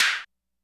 percussion02.wav